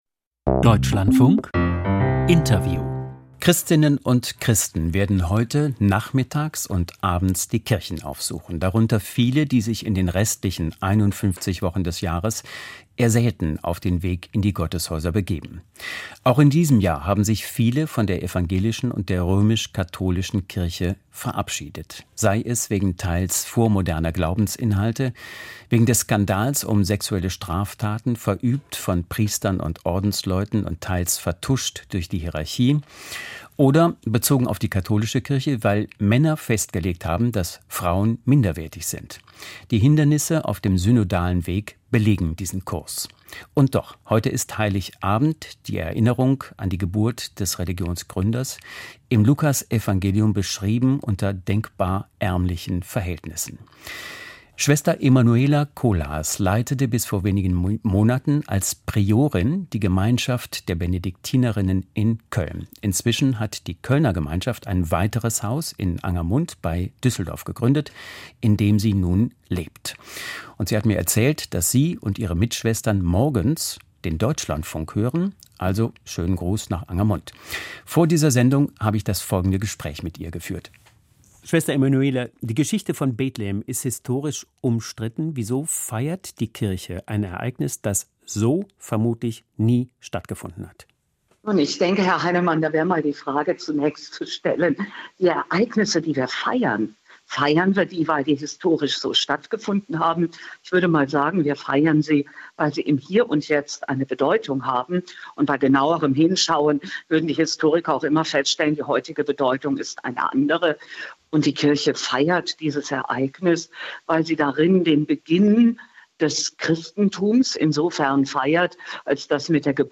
Weihnachten in Krisenzeiten - Benediktinerin: Die zentrale Botschaft lautet "fürchtet Euch nicht"